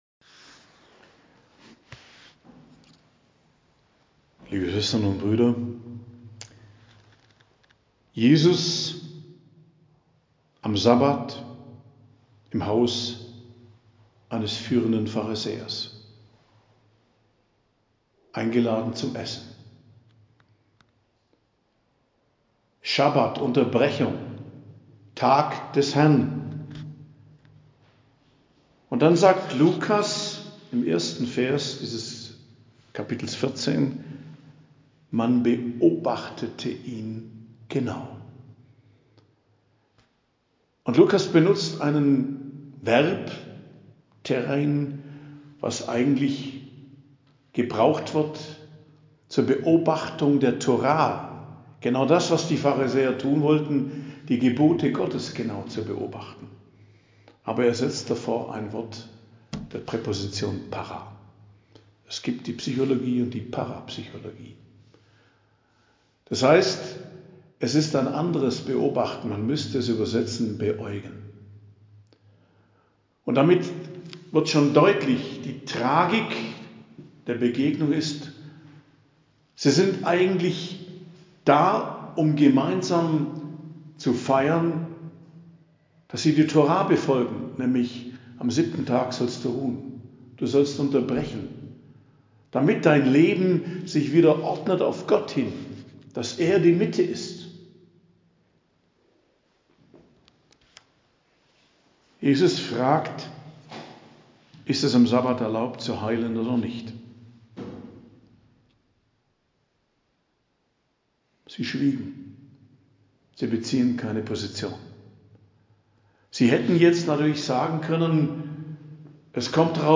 Predigt am Freitag der 30. Woche i.J., 3.11.2023